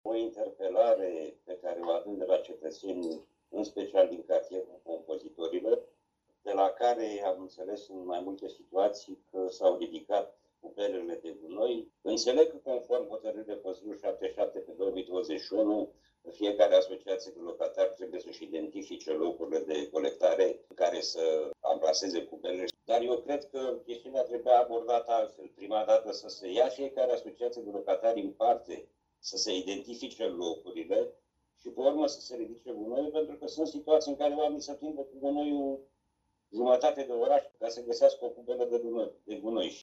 Interpelări la începutul și finalul ședinței Consiliului Local Constanța.
Înainte de votul proiectelor aflate pe ordinea de zi, consilierul local AUR, Ovidiu Cupșa, a adus în atenție problemele legate de pubelele din anumite zone ale orașului.